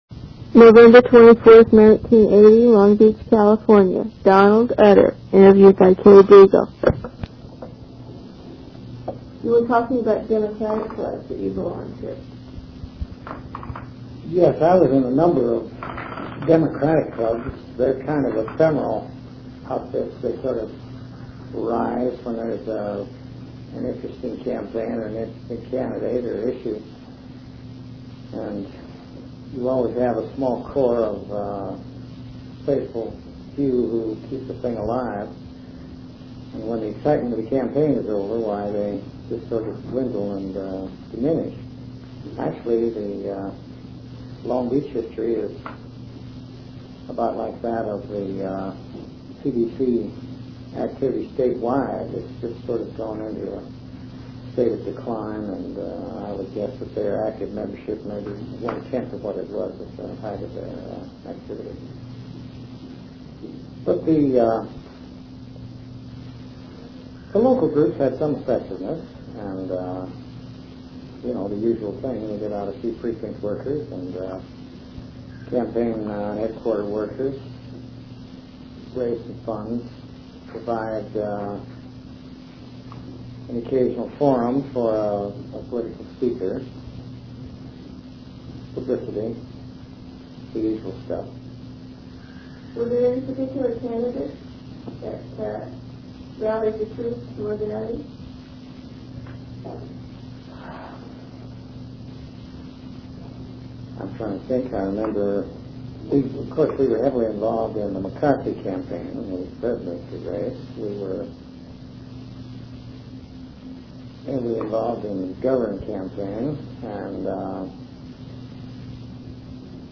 audio interview #4 of 5